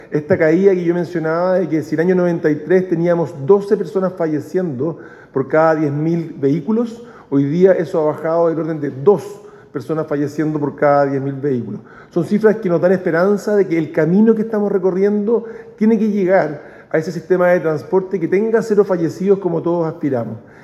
Al respecto, el ministro de Transportes, Juan Carlos Muñoz, sostuvo que la disminución porcentual se debe, entre otras cosas, a la mayor legislación en cuanto al consumo de alcohol y drogas, las sanciones a quienes manejan a exceso de velocidad, a los vehículos más seguros y la mejor infraestructura existente hoy en día.